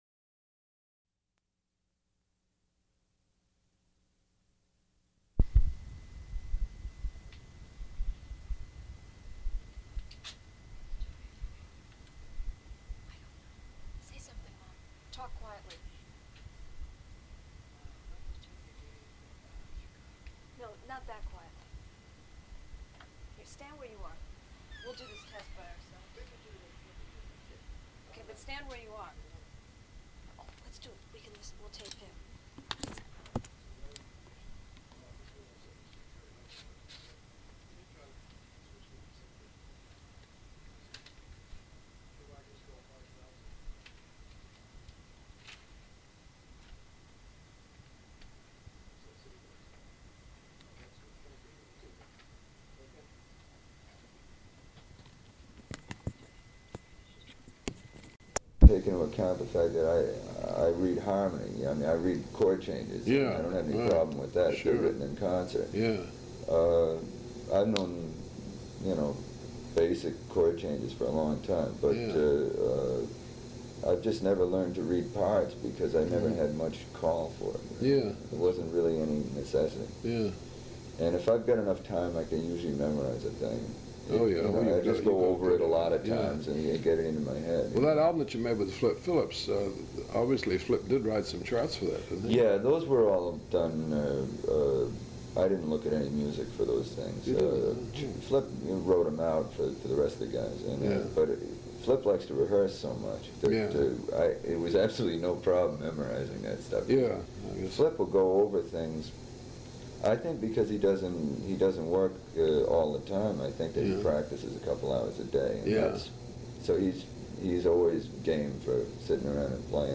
Scott Hamilton Interview
Item from Leonard Feather Collection: Leonard Feather interviews Scott Hamilton about his career. Scott Hamilton is an American jazz saxophonist. 18:52 Music until the end.